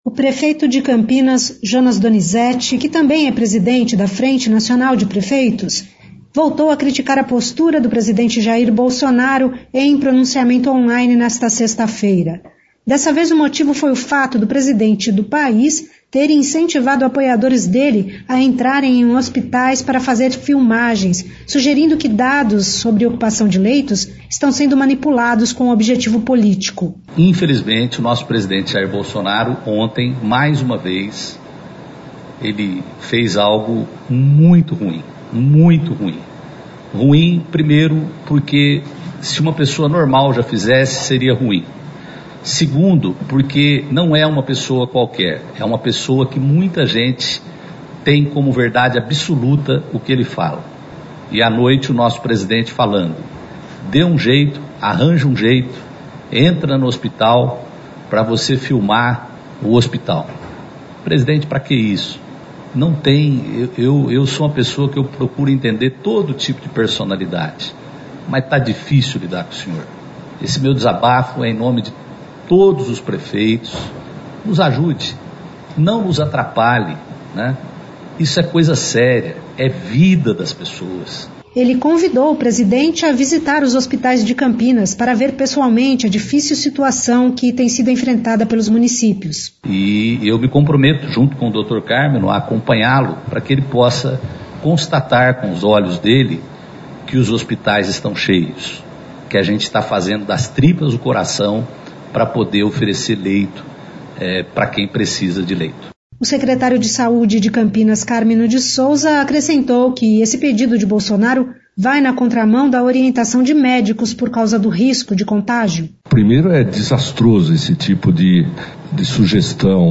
O Prefeito de Campinas, Jonas Donizette, que também é presidente da Frente Nacional de Prefeitos, voltou a criticar a postura do presidente Jair Bolsonaro, em pronunciamento online, nesta sexta-feira. Dessa vez, o motivo foi o fato do presidente do país ter incentivado apoiadores dele a entrarem em hospitais para fazer filmagens, sugerindo que dados sobre ocupação de leitos estão sendo manipulados com objetivo político.